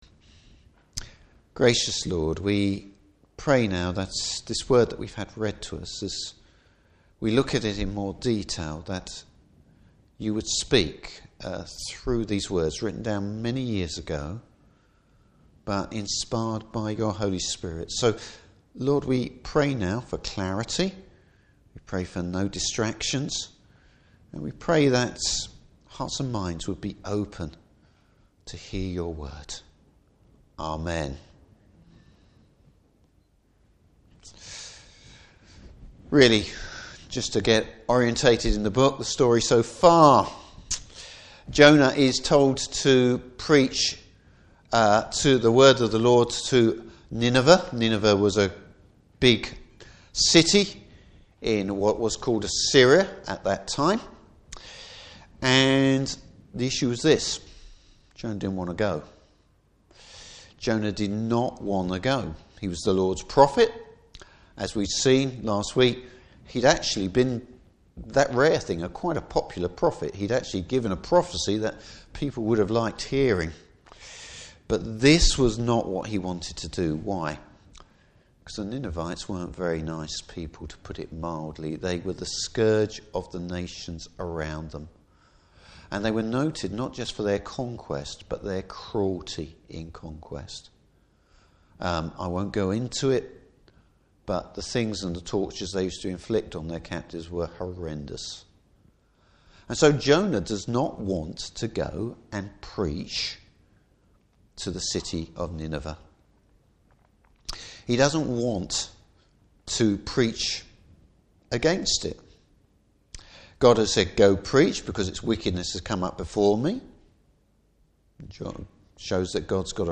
Service Type: Evening Service The Lord is the only place to look for salvation!